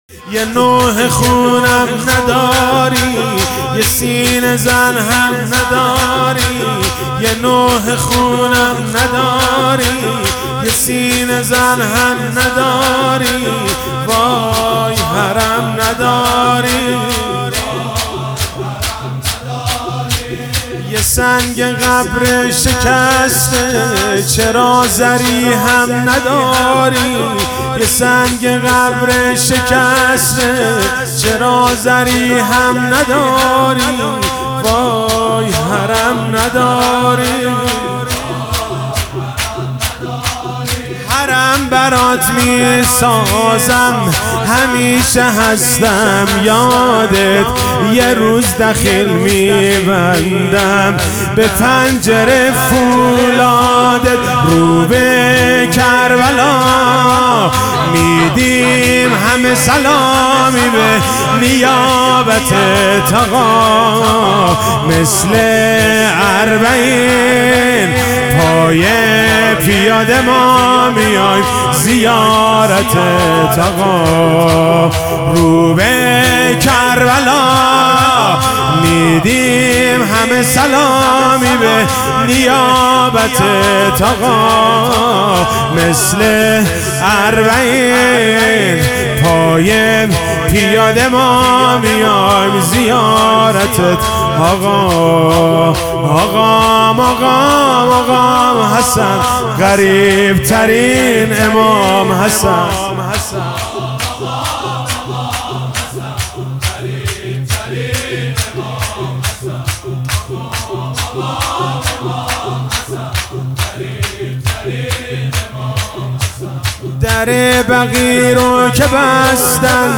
فاطمیه1400